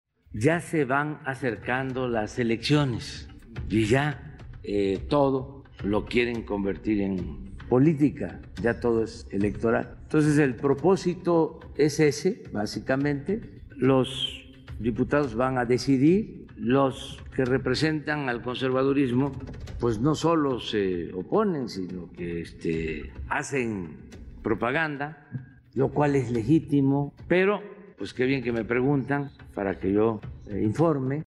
En la conferencia diaria, el mandatario destacó que ha buscado una base legal, a diferencia del pasado, cuando se ejercían casi discrecionalmente los recursos.